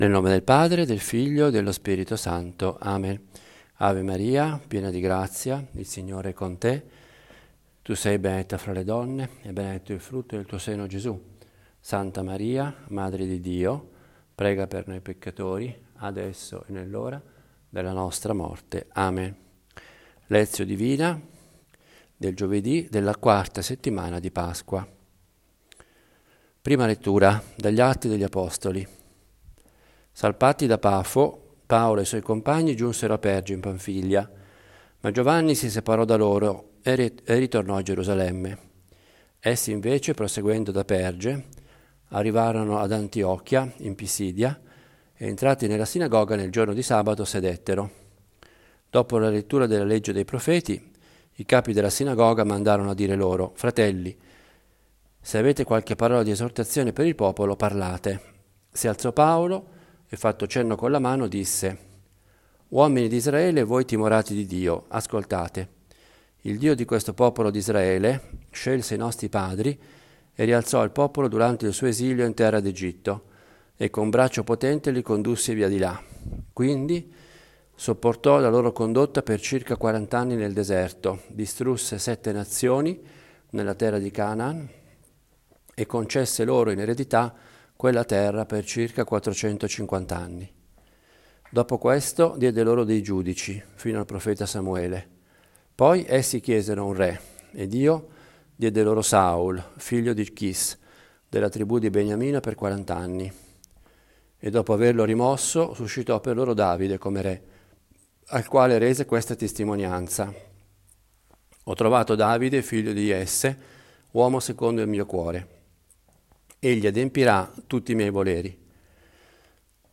Lectio Divina di giovedì della IV settimana di Pasqua (At 13,13-25 Sal 88 Gv 13,16-20).